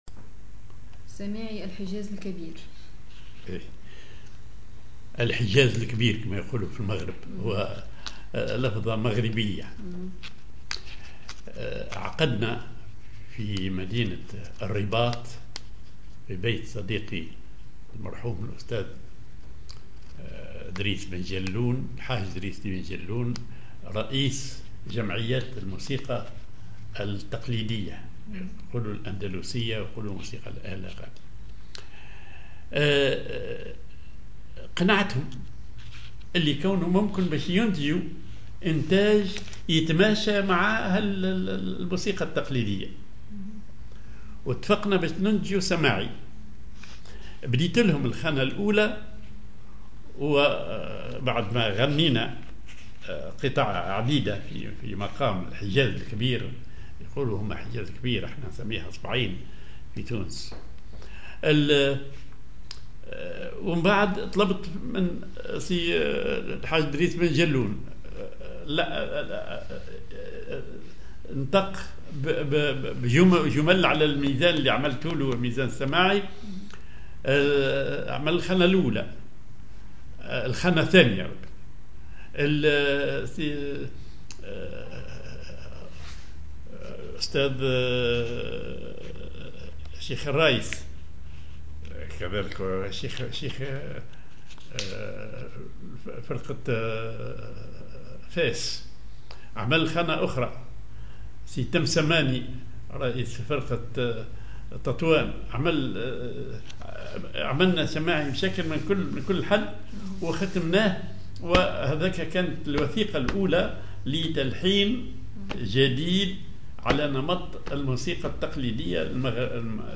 Maqam ar حجازالكبير
Rhythm ID سماعي ثقيل
genre سماعي